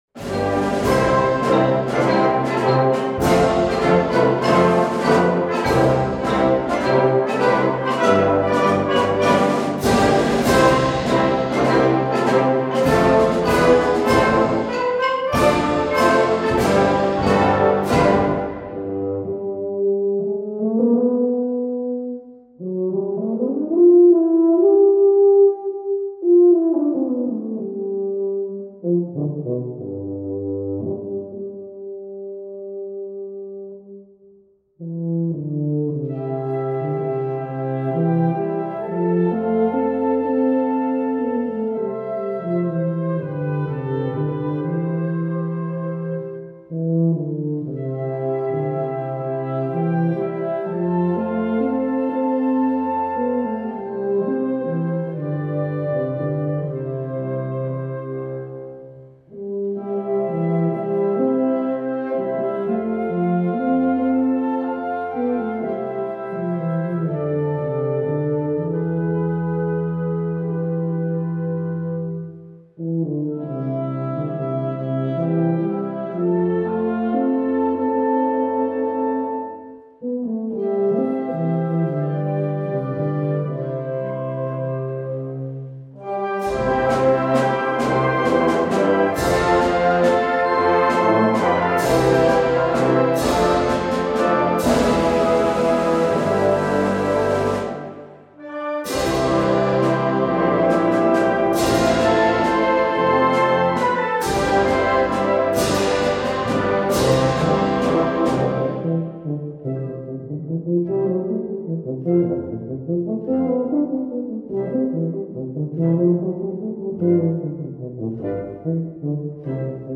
Voicing: Tuba Solo